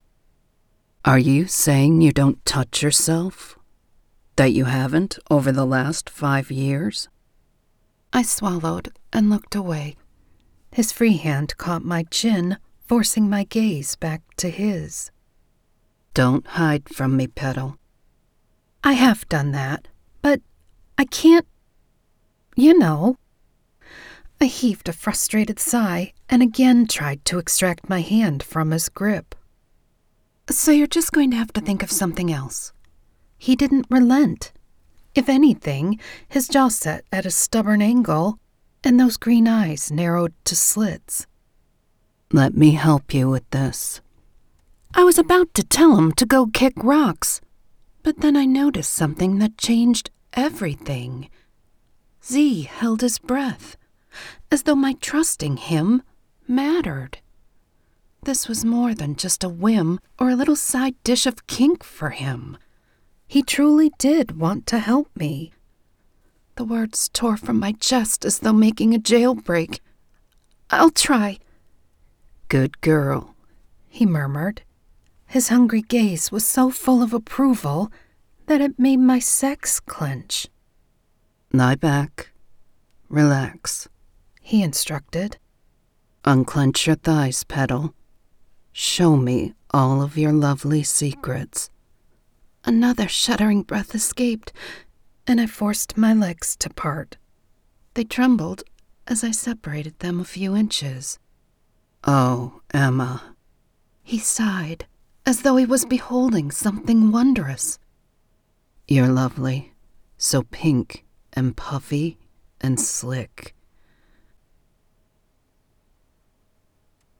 Narrator
Accent Capabilities: Full narrative: American, Midwest, and Southern
Secondary characters: English/British, Canadian, Russian, Middle Eastern, Hispanic, Asian and Irish.